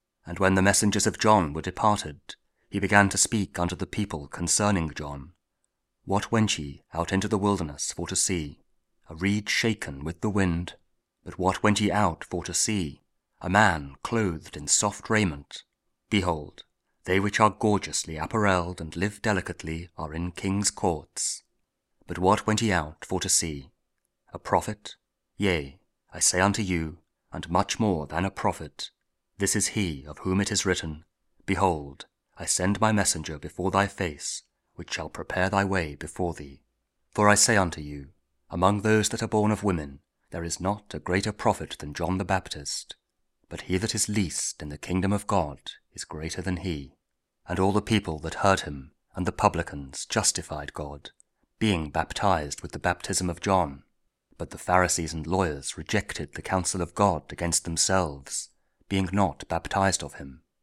Luke 7: 24-30 – Advent Week 3, Thursday (Audio Bible, Spoken Word)